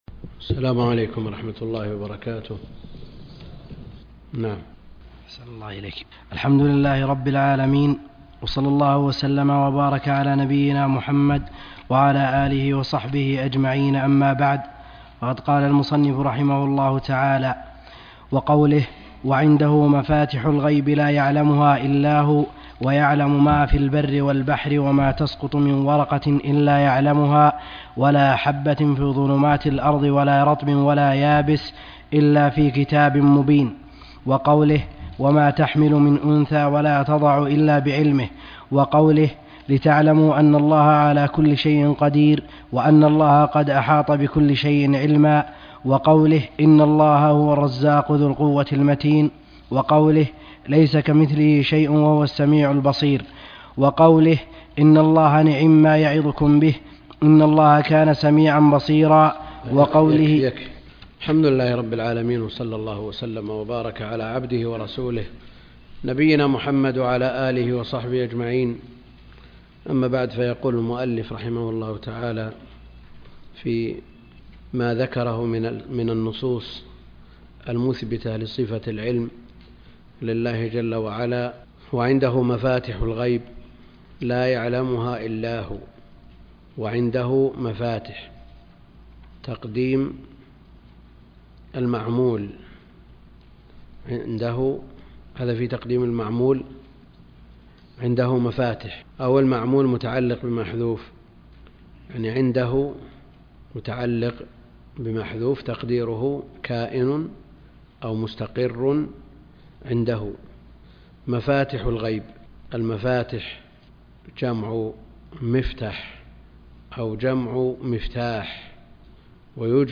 الدرس (10) شرح العقيدة الواسطية - الدكتور عبد الكريم الخضير